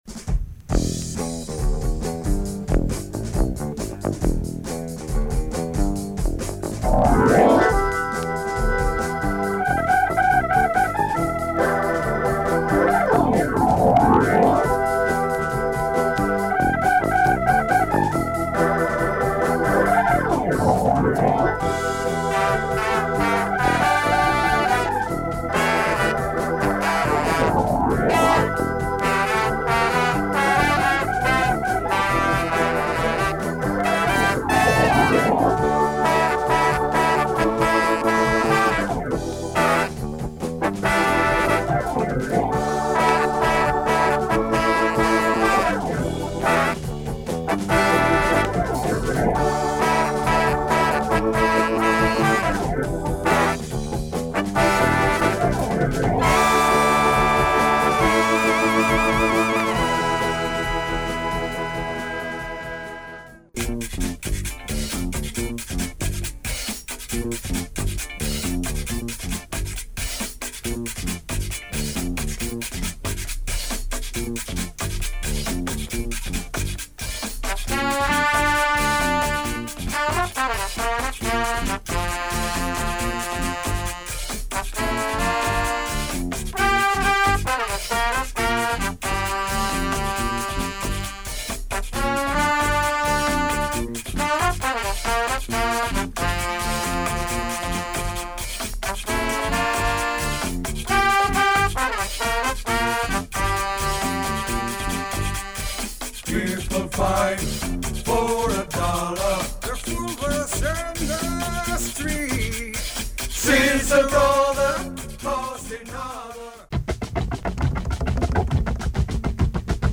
Rare groove from Barcelona !
Really dope funk soul with breaks.